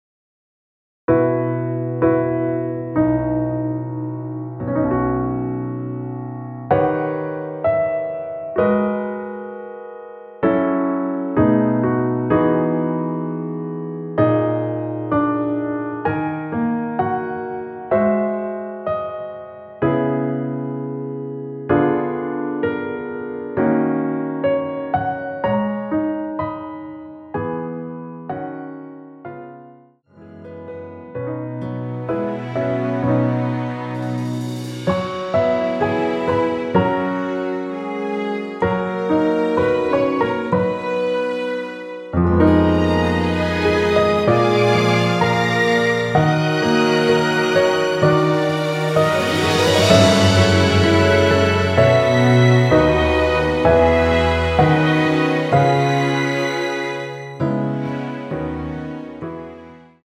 전주 없이 시작하는 곡이라 노래하기 편하게 전주 1마디 만들어 놓았습니다.(미리듣기 확인)
원키에서(+5)올린 MR입니다.
앞부분30초, 뒷부분30초씩 편집해서 올려 드리고 있습니다.